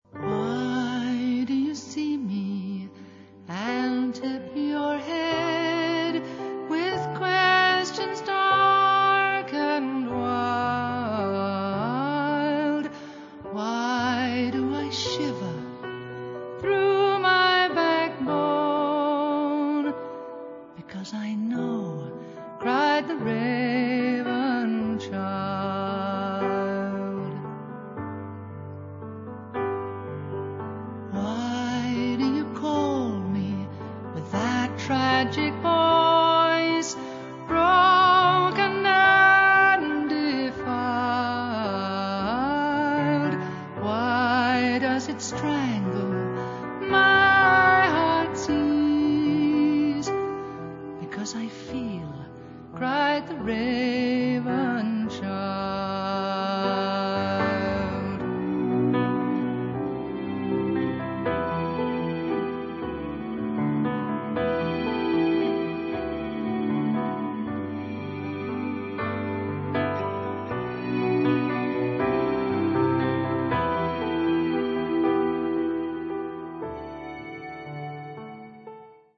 First part, 1:19 sec, mono, 22 Khz, file size: 310 Kb.